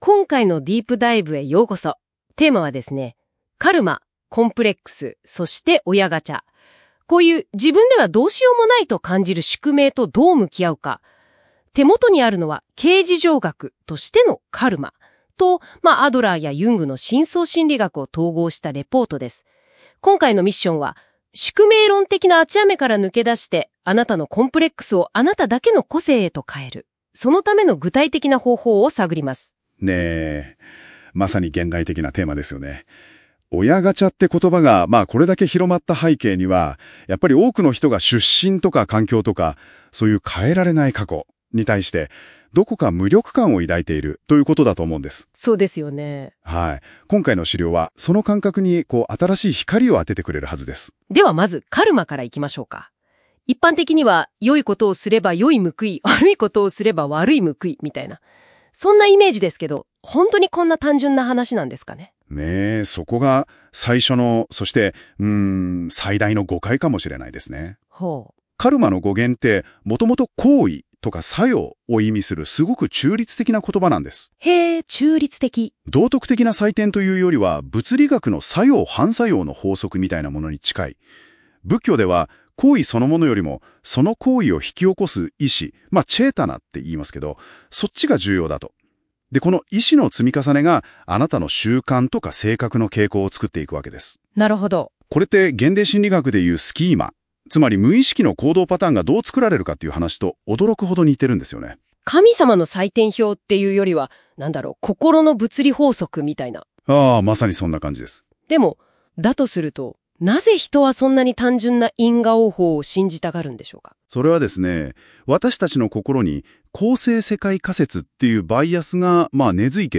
【音声解説】宿命論を脱する「親ガチャ」とコンプレックス克服法